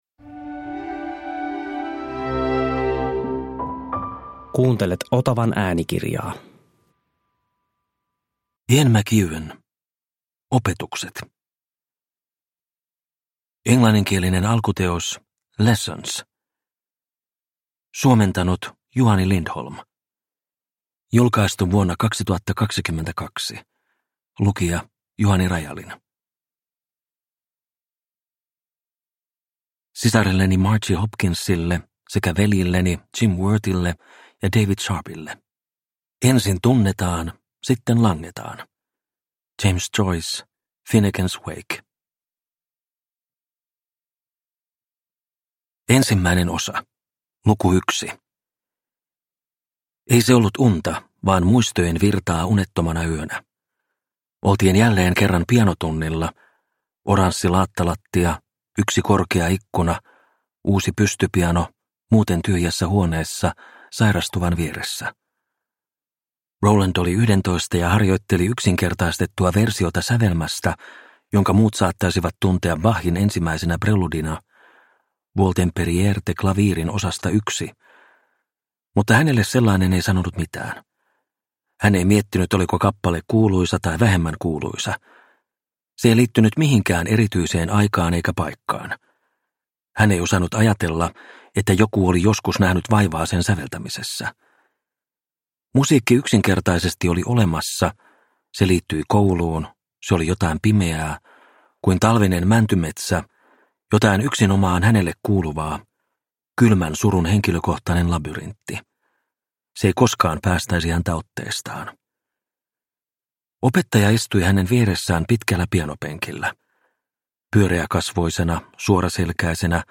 Opetukset – Ljudbok – Laddas ner